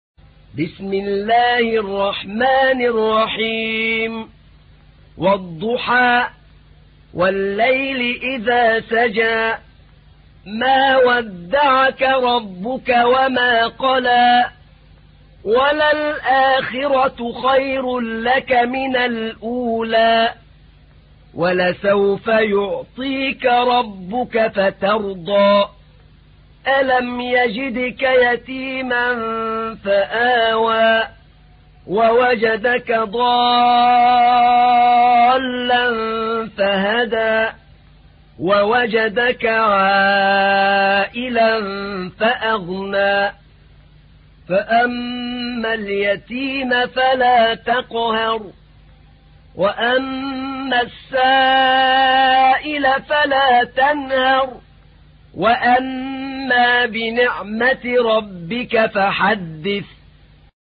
تحميل : 93. سورة الضحى / القارئ أحمد نعينع / القرآن الكريم / موقع يا حسين